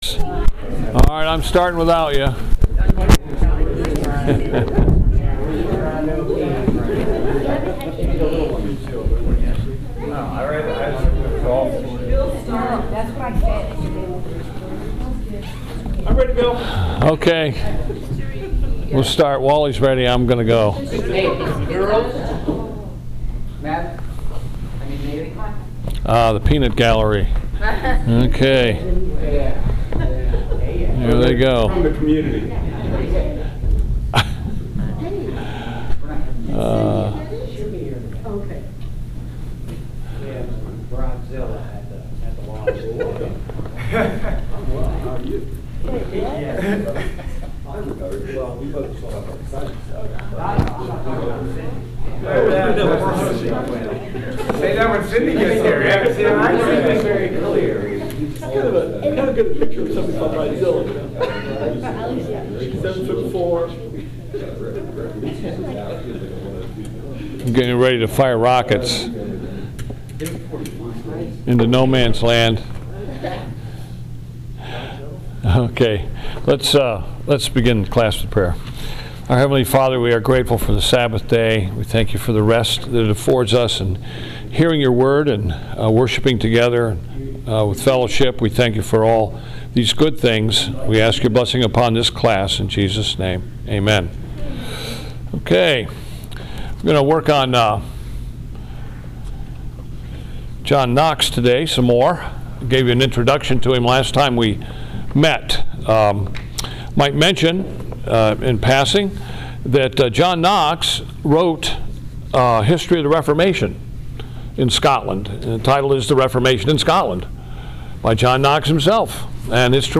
Presbyterian History – Lecture 8